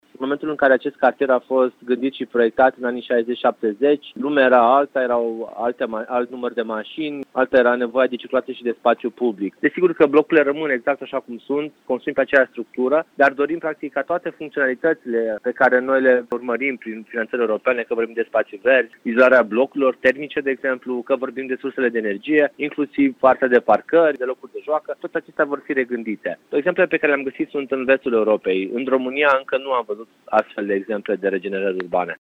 Vor rămâne în picioare doar blocurile, construite în anii 70, iar restul – parcări, spații verzi sau parcuri vor fi supuse schimbării, explică viceprimarul Ruben Lațcău.